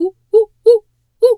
monkey_2_chatter_01.wav